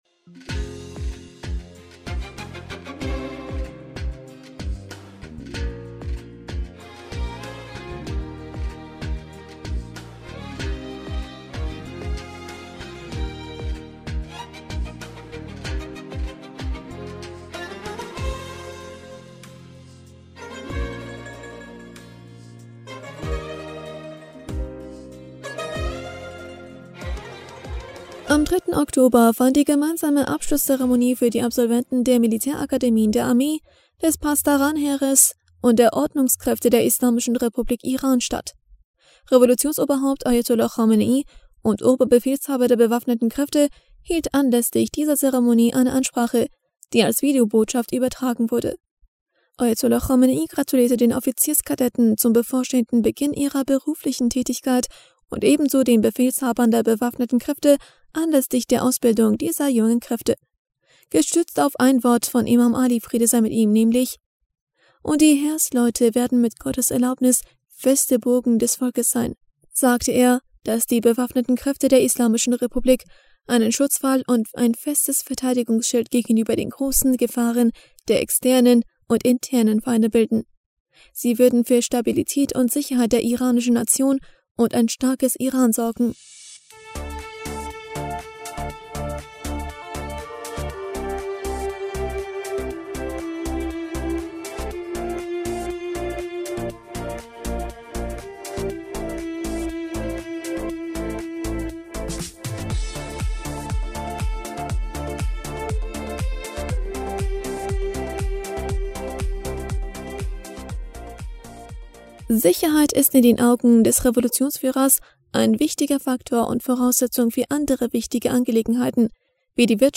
Ansprache vor den Absolventen der Militärakademie
Revolutionsoberhaupt Ayatollah Khamenei und Oberbefehlshaber der Bewaffneten Kräfte hielt anlässlich dieser Zeremonie eine Ansprache, die als Videobotschaft übertragen wurde.